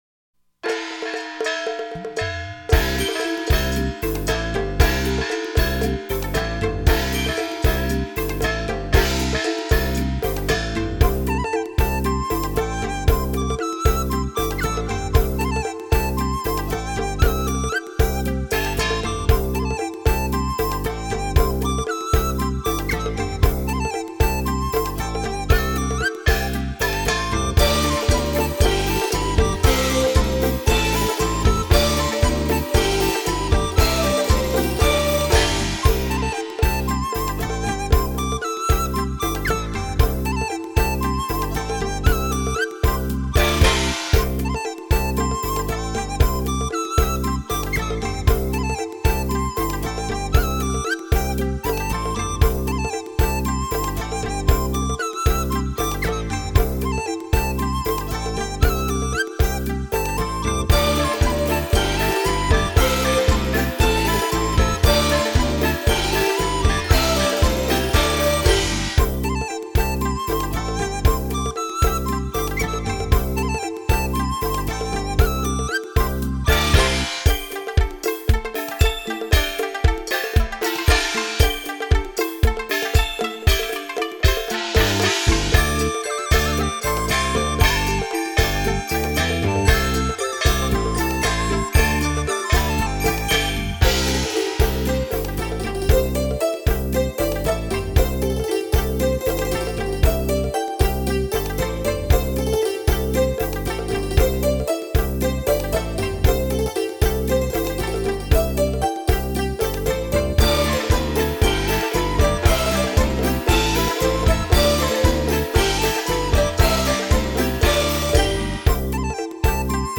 民乐
有什么样的乐器，能像胡琴、把乌、笙、笛、锣、鈸和唐鼓无间的合作，尽兴地制造出喜洋洋的氛围？